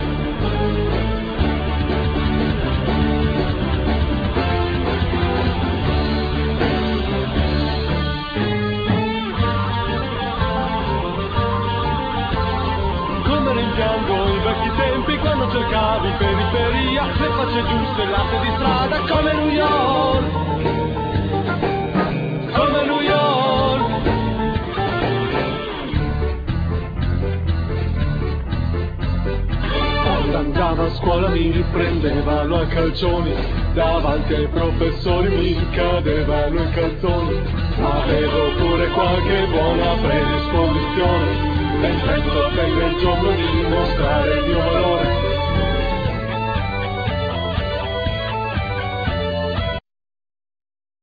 Drums
Keyboards
Vocals
Guitar
Bass
Tenor Saxophone
Backing vocals
Timpani